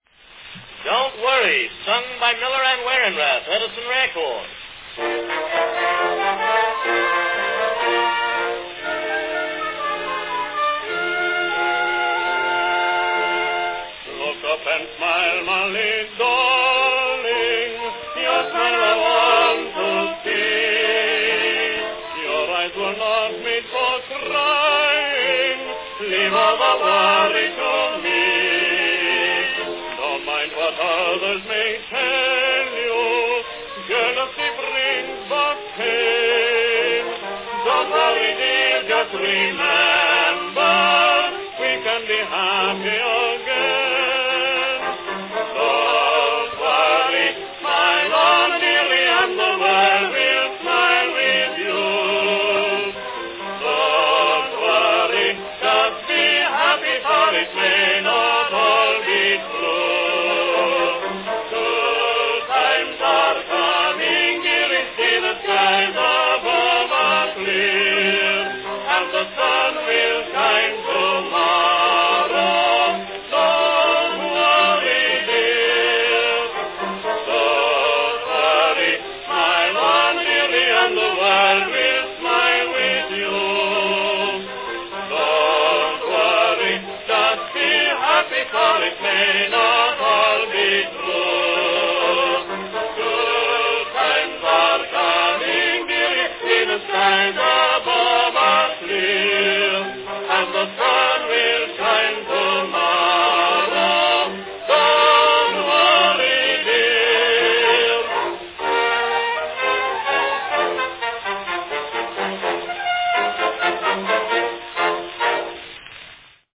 Category Duet
One of the latest and best march ballads of the "Honey Boy"
Orchestra accompaniment.